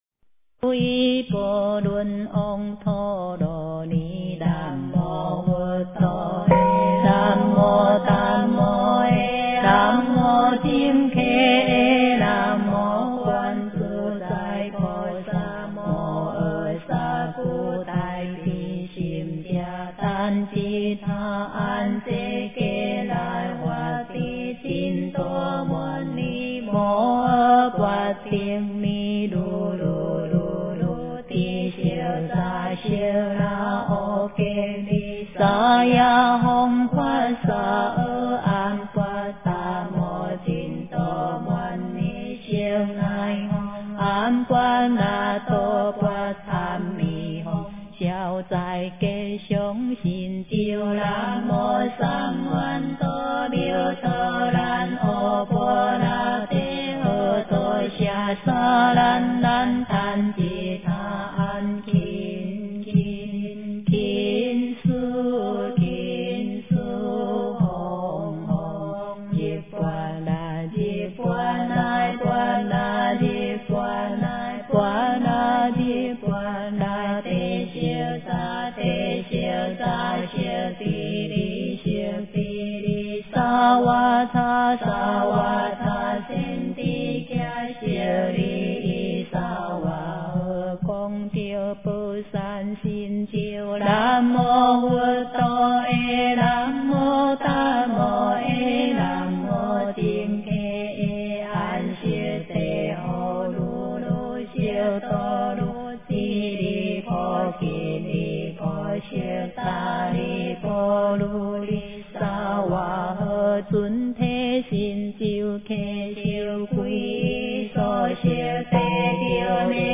经忏
佛教音乐